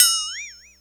• Long Tail Reverb Ride Cymbal Audio Clip F# Key 03.wav
Royality free ride single shot tuned to the F# note. Loudest frequency: 6780Hz
long-tail-reverb-ride-cymbal-audio-clip-f-sharp-key-03-yTP.wav